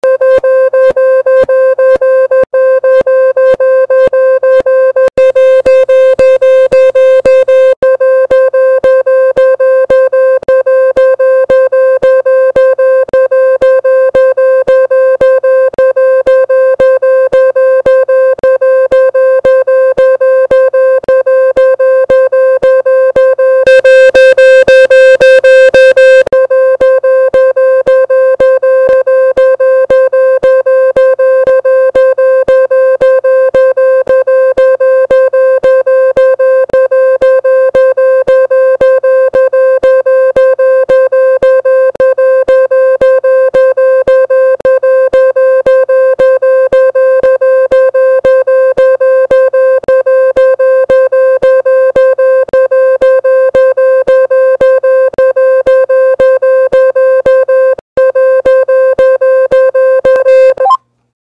Аудиофайлы генерируют специфические частоты, которые помогают вытолкнуть загрязнения без механического вмешательства.
Звук для очистки динамика iPhone от пыли